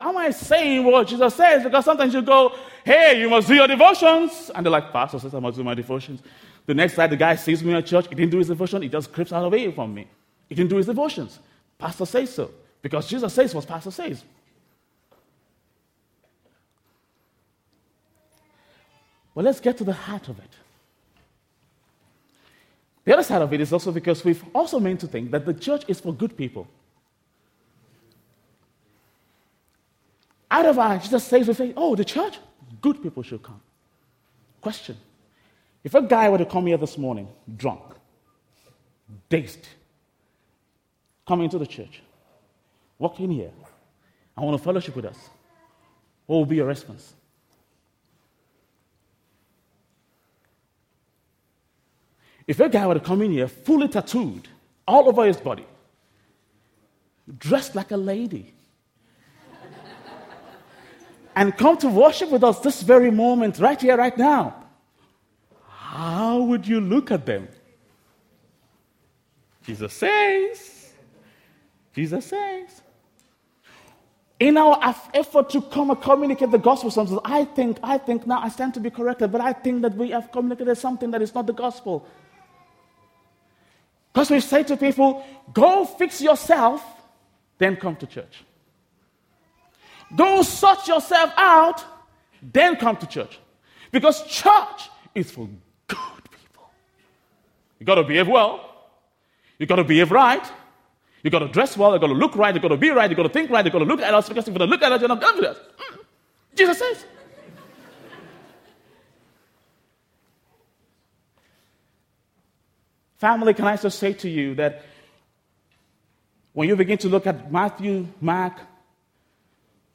Family Camp – SIC Sermons
SIC Combined Family Camp